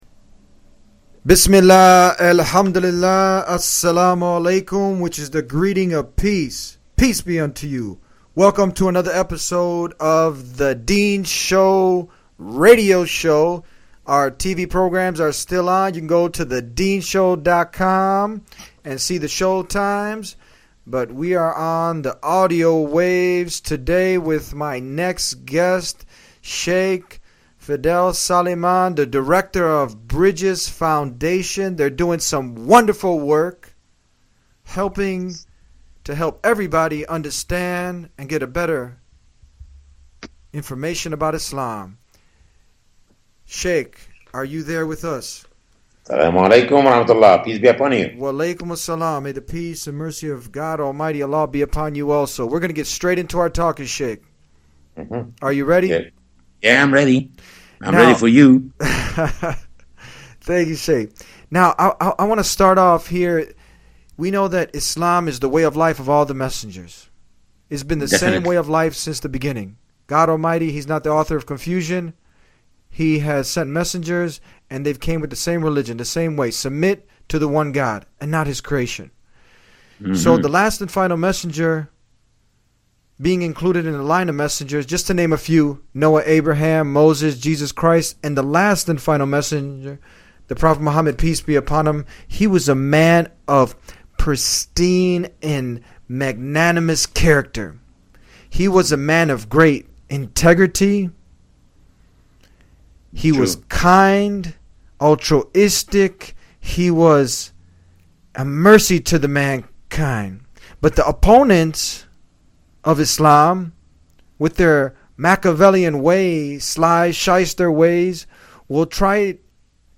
Arguments Made Against Muhammad (radio)